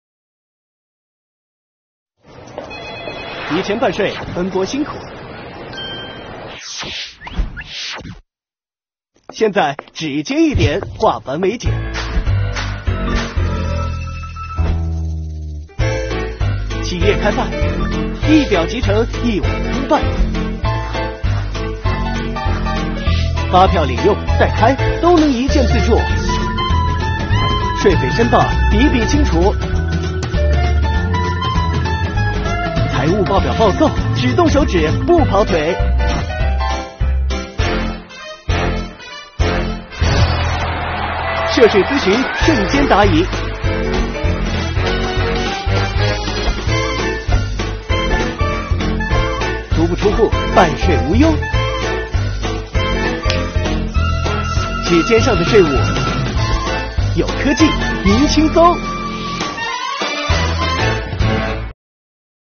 指尖，轻松灵动，踏着轻快的节奏，在电子税务局操作界面上点击，化烦琐奔波为轻松便捷。
特别是手指舞蹈部分令人难忘，将专业的办税知识融入手指舞蹈中，配合动感时尚的爵士乐，易于观众接受。